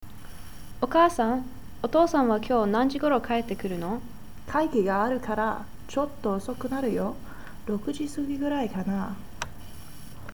Japanese Conversation Set #8